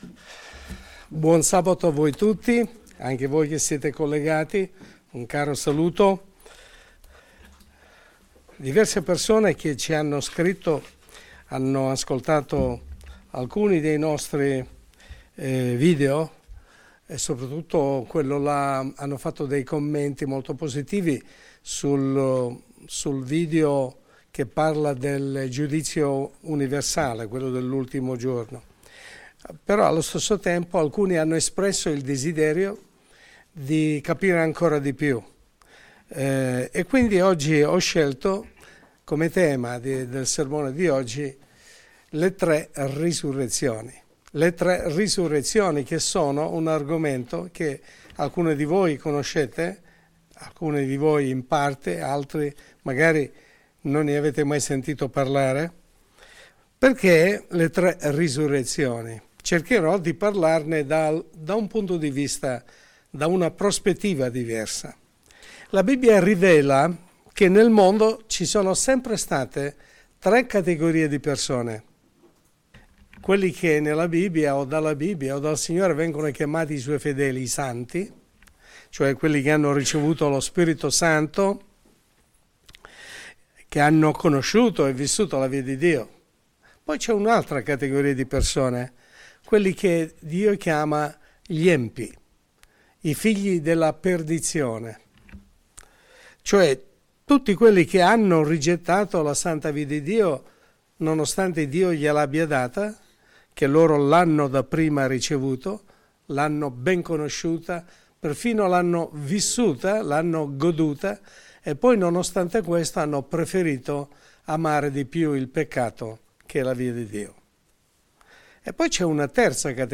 Messaggio pastorale